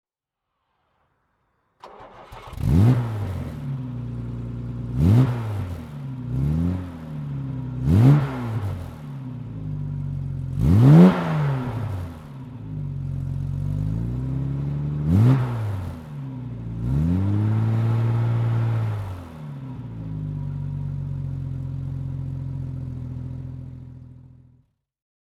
Jaguar XK150 S "Tow Car" (1959) - Starten und Leerlauf